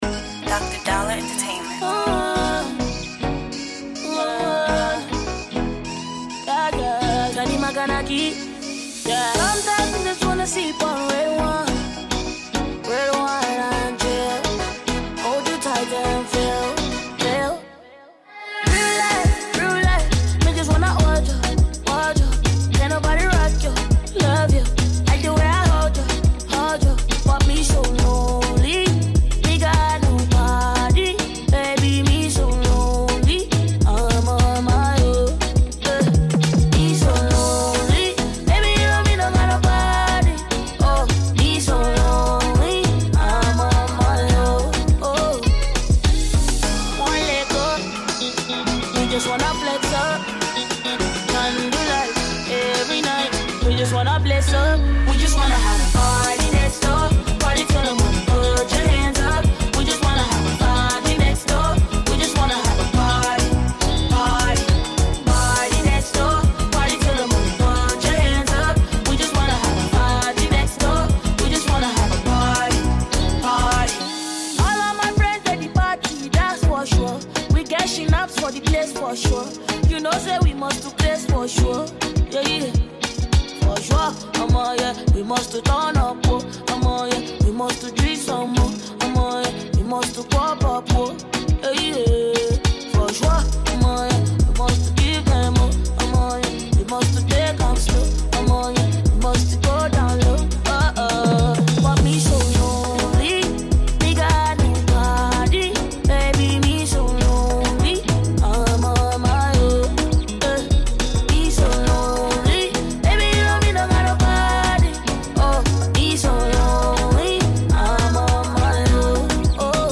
banging tune
smoky jam
adding the contemporary pop sound to spice up the song